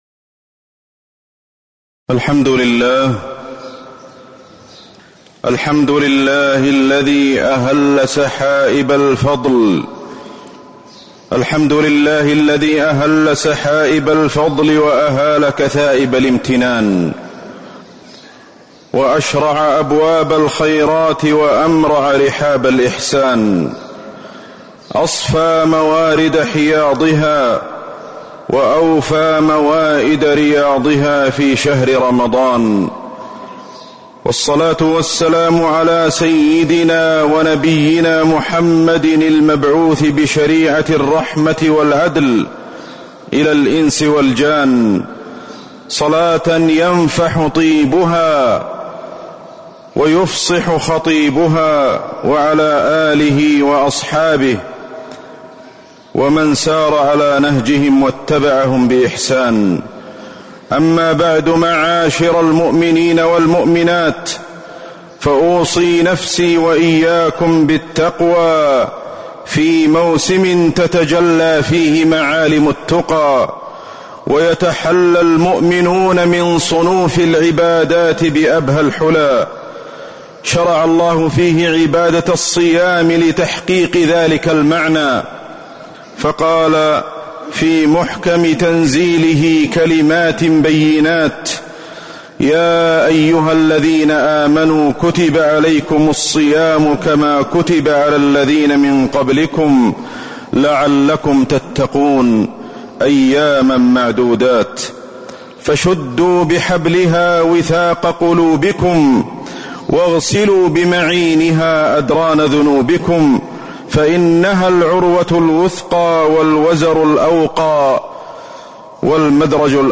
تاريخ النشر ٢ رمضان ١٤٤٤ هـ المكان: المسجد النبوي الشيخ: فضيلة الشيخ أحمد بن علي الحذيفي فضيلة الشيخ أحمد بن علي الحذيفي رمضان موسم الخيرات